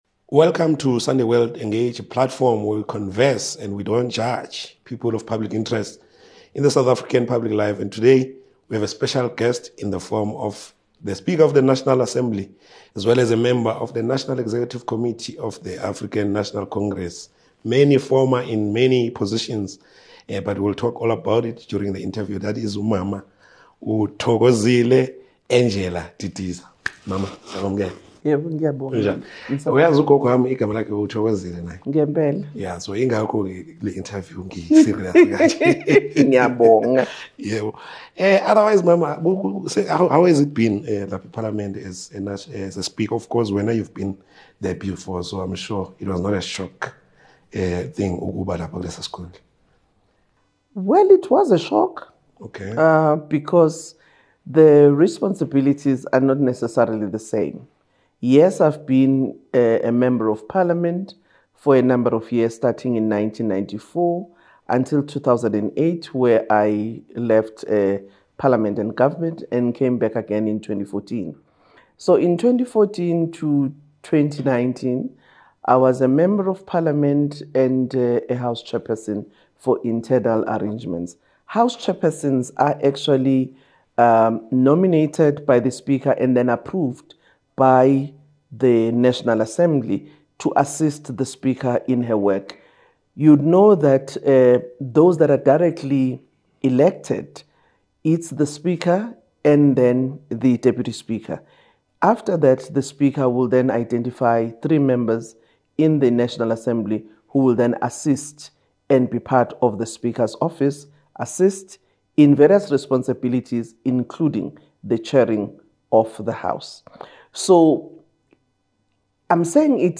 In this gripping interview, Thoko Didiza sits down with Sunday World Engage to unpack her journey from Minister of Agriculture to Speaker of the National Assembly. She boldly reflects on power, purpose, and the ANC's vision—revealing why her loyalty was never about positions. This candid conversation sheds light on land reform, governance, and the heart of ethical leadership in South Africa.